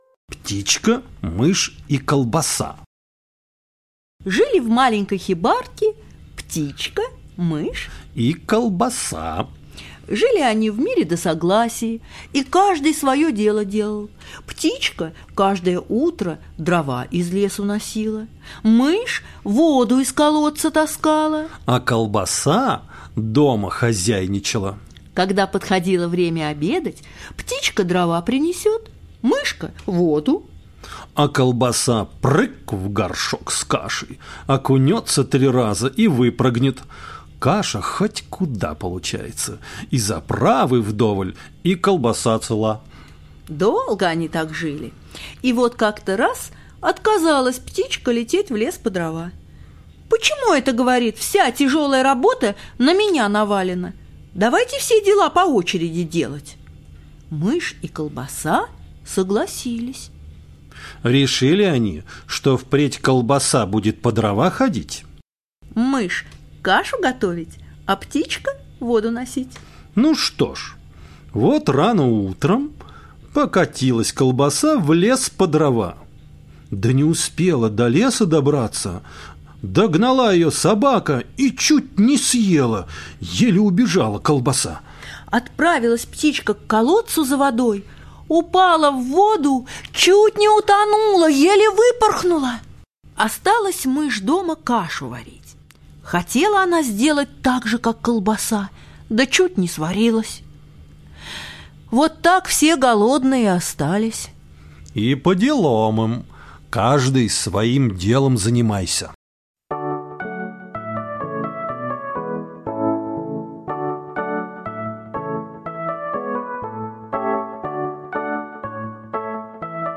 Птичка, мышь и колбаса - латышская аудиосказка - слушать онлайн